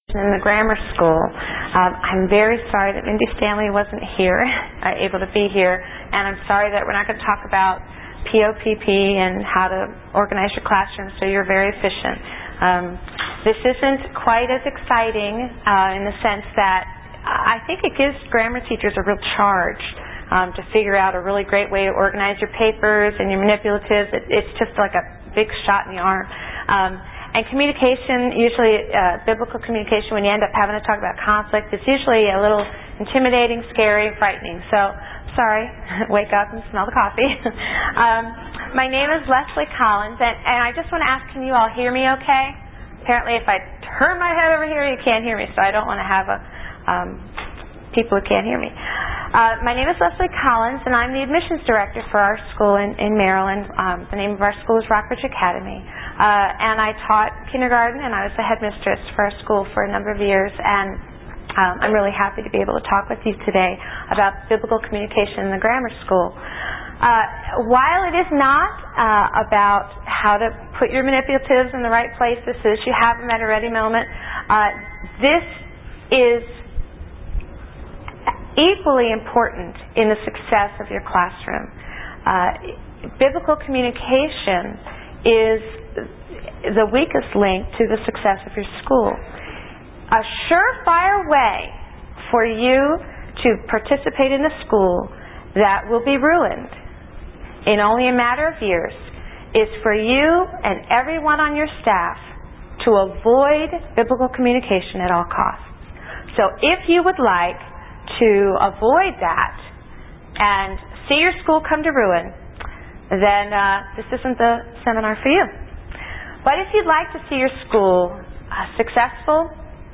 2003 Workshop Talk | 0:45:48 | K-6, General Classroom